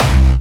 VEC3 Bassdrums Dirty 27.wav